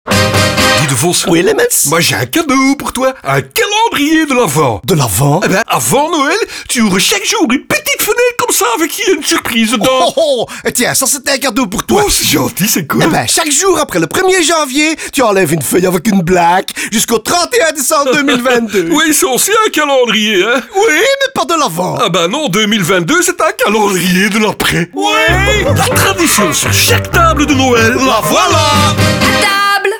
Le célèbre duo fera la promotion de ce calendrier à sa manière dans un spot radio, mais ce gadget reviendra aussi régulièrement dans le reste de la campagne de fin d'année.
Devos&Lemmens_FR30s_CalendrierDeLavent_Radio.wav